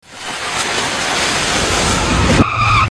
Car Crash Reverse